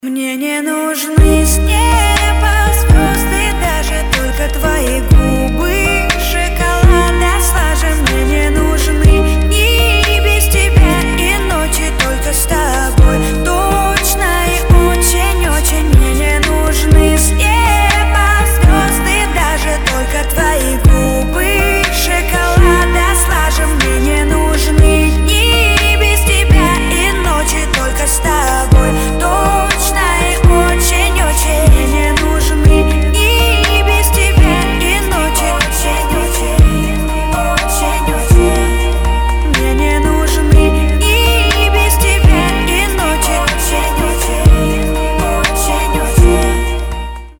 • Качество: 320, Stereo
женский вокал
спокойные
chillout
chilltrap